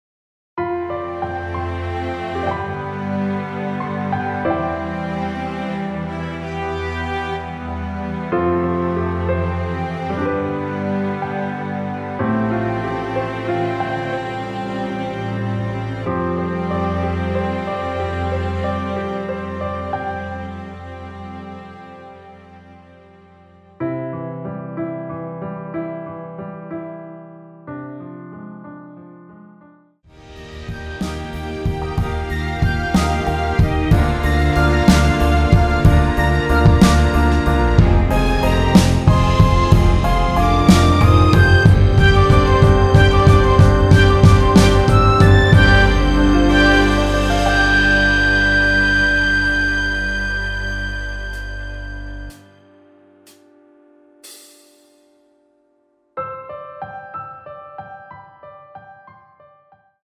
3분 14초경 무반주 후 노래 들어가는 부분 박자 맞추기 쉽게 카운트 추가하여 놓았습니다.(미리듣기 확인)
원키에서(-1)내린 MR입니다.
앞부분30초, 뒷부분30초씩 편집해서 올려 드리고 있습니다.
중간에 음이 끈어지고 다시 나오는 이유는